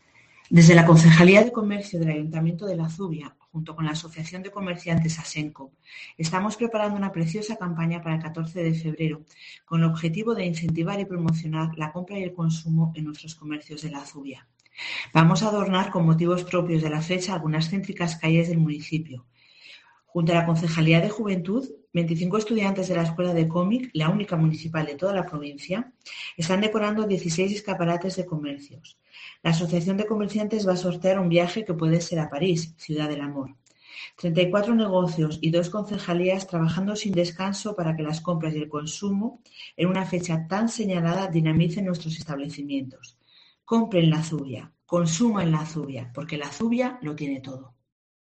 la concejala de Comercio del Ayuntamiento de La Zubia, Natalia Arregui